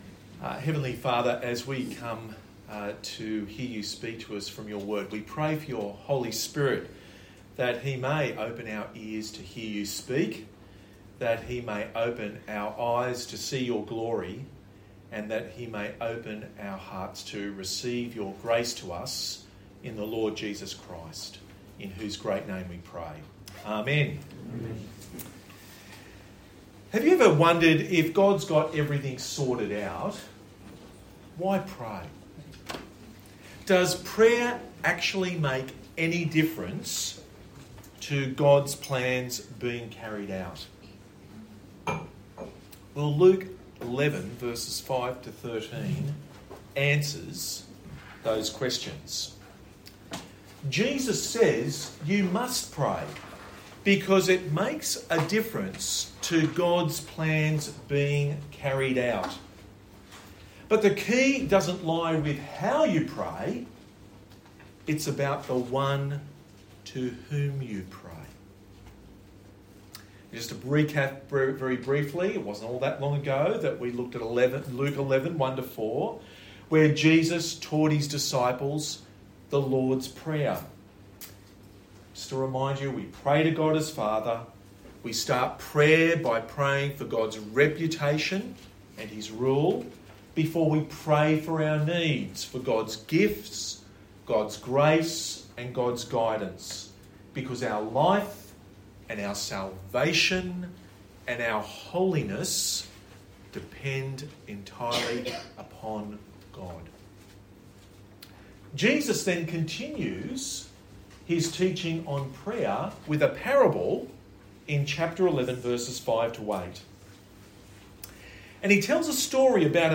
Church Camp Talk